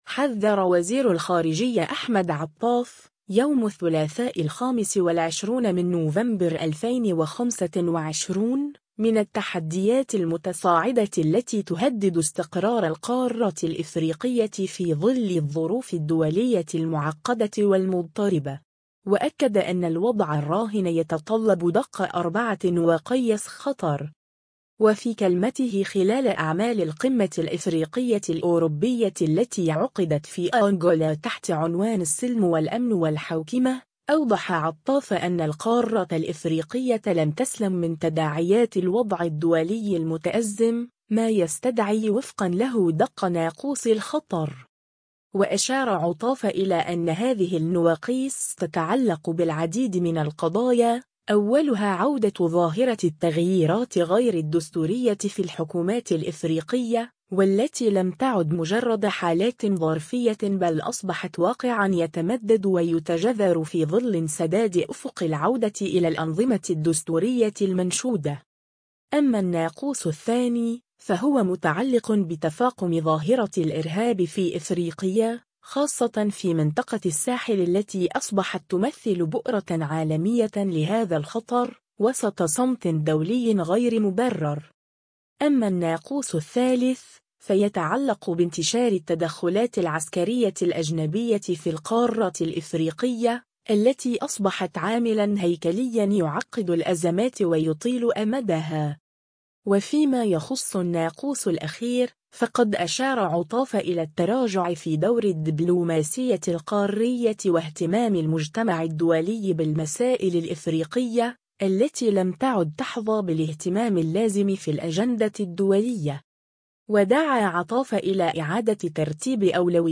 وفي كلمته خلال أعمال القمة الإفريقية-الأوروبية التي عُقدت في أنغولا تحت عنوان “السلم والأمن والحوكمة”، أوضح عطاف أن القارة الإفريقية لم تسلم من تداعيات الوضع الدولي المتأزم، ما يستدعي وفقًا له دق ناقوس الخطر.